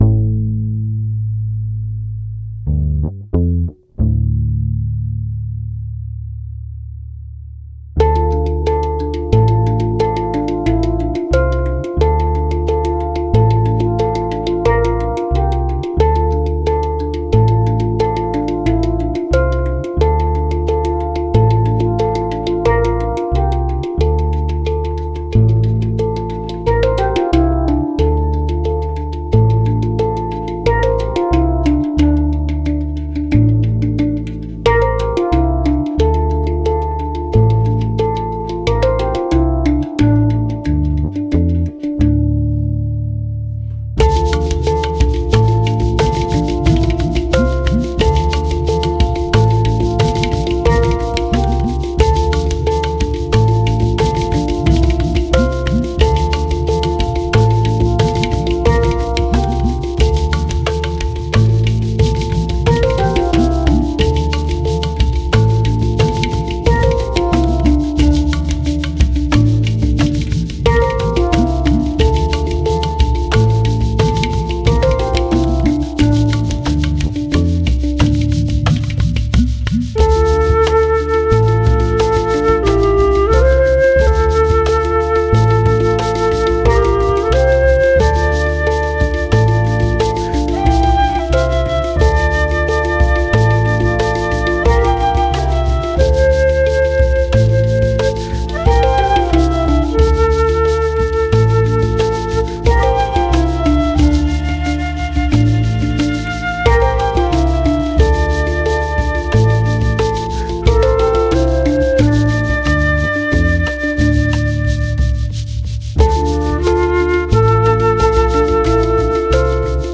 flûtes, doudouk, percussions.